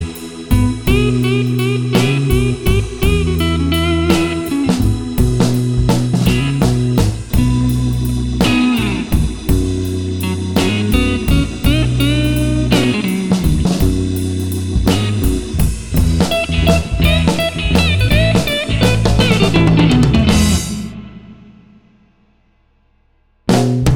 Live Pop (1960s) 3:43 Buy £1.50